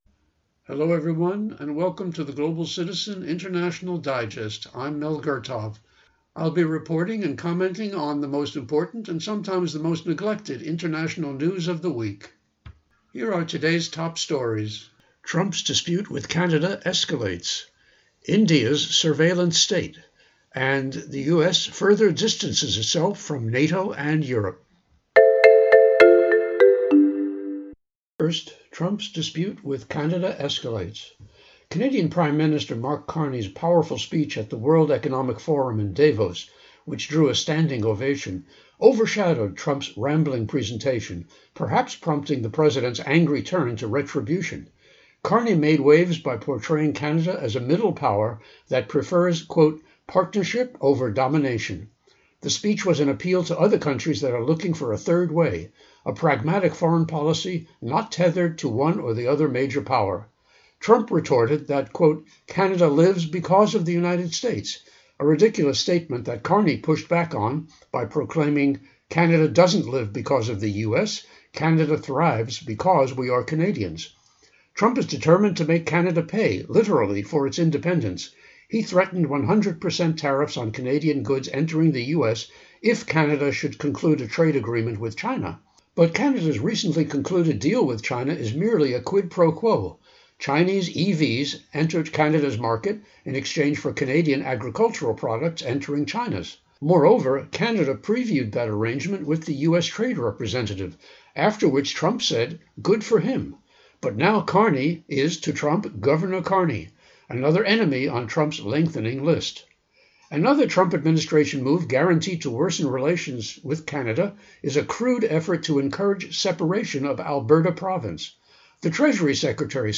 Program:: Evening News
Genre(s): Public Affairs